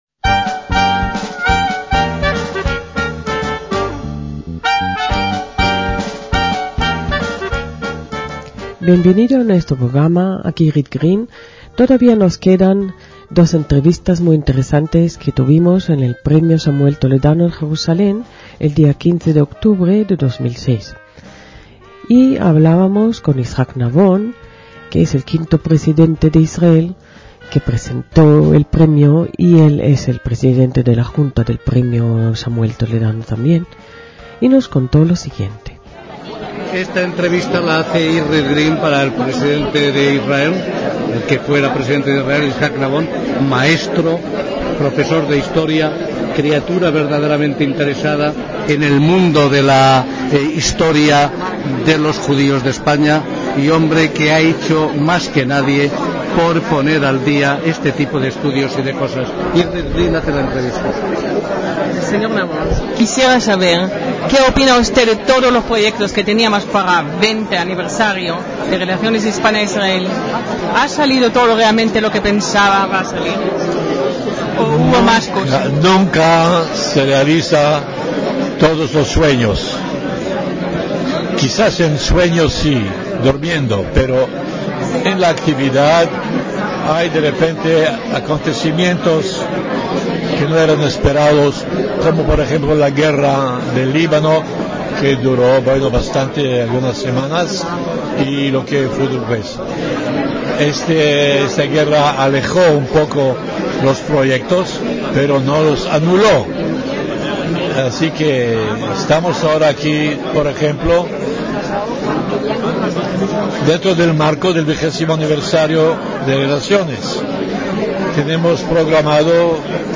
DECÍAMOS AYER (6/11/2006) - En la ceremonia de entrega de los Premios Samuel Toledano 2006 estuvieron presentes muchas personalidades, algunas de las cuales hablaron para Radio Sefarad.